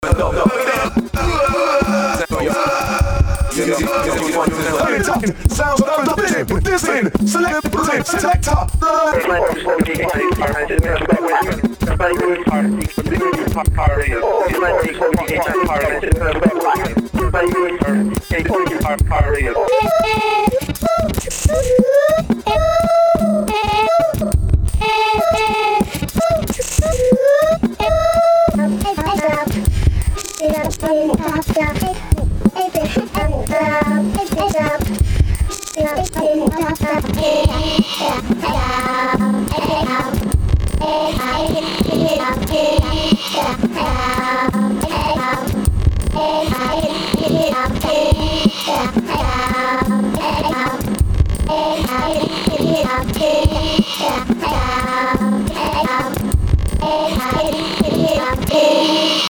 scanning through audio with phasors